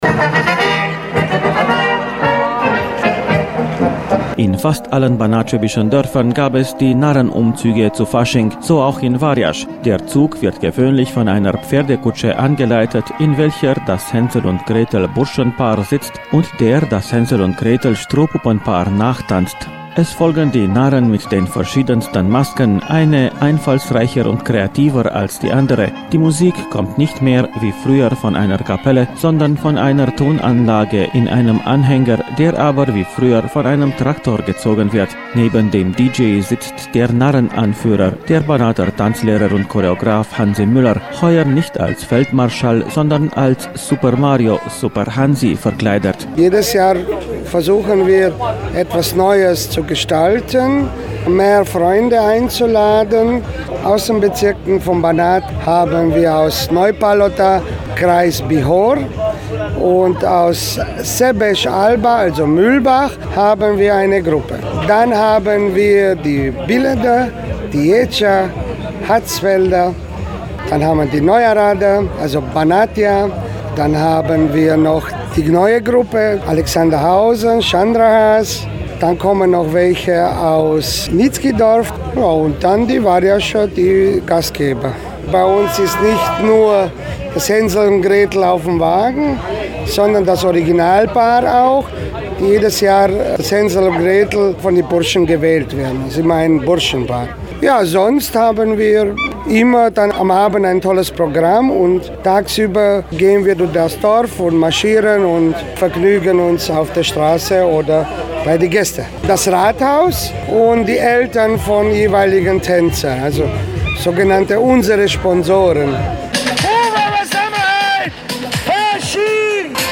Die deutschen Jugendtanzgruppen aus West-Rumänien feierten ihr Faschingsfest am 15. Februar in der Temescher Gemeinde Warjasch.